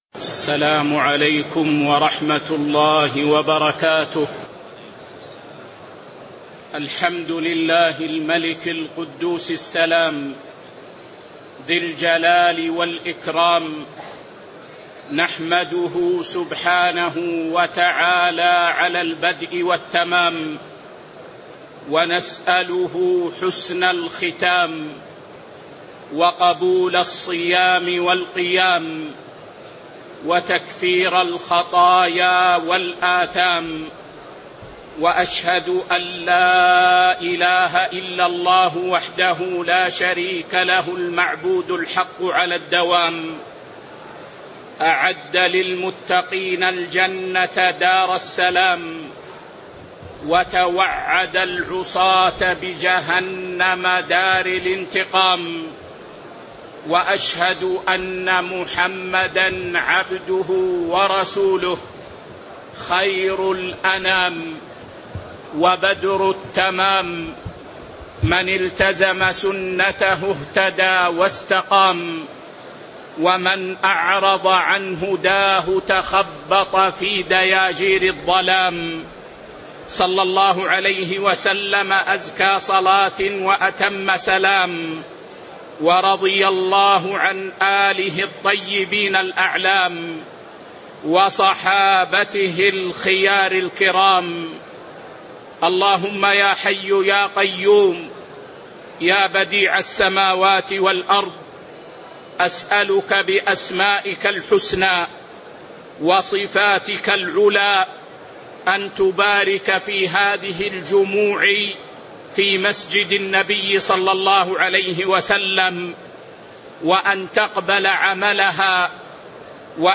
تاريخ النشر ٢٨ رمضان ١٤٤٤ هـ المكان: المسجد النبوي الشيخ